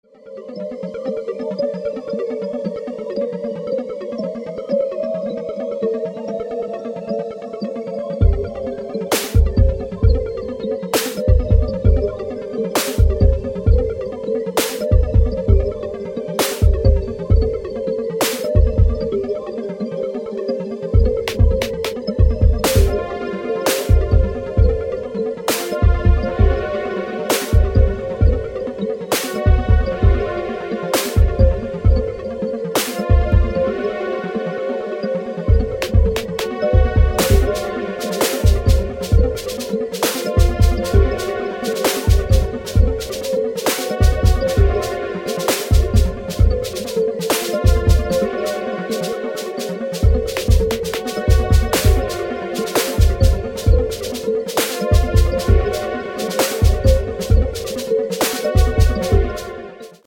sinister acidic techno